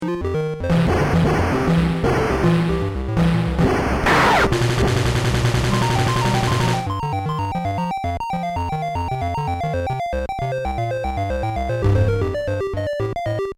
dual mono converted
music+sound effects